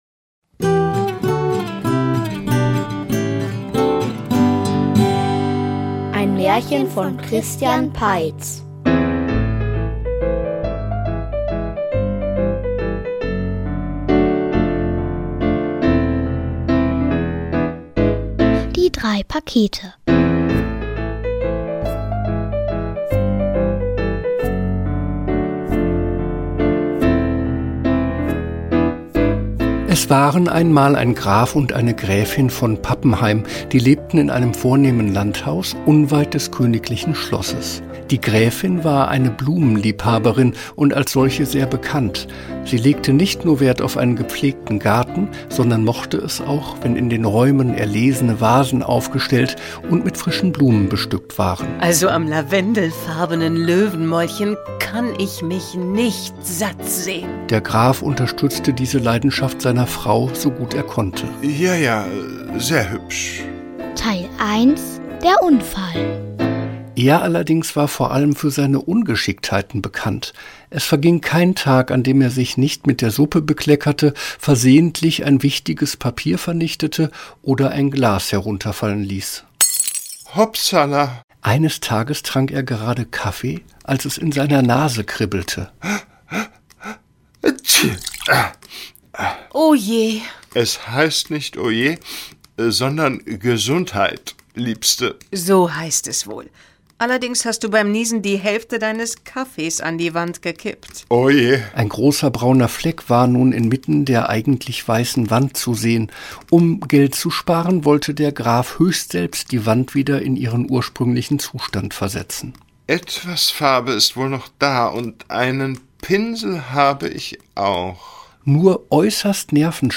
Märchen-Hörspiele – Podcast